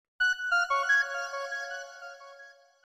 25. birdsong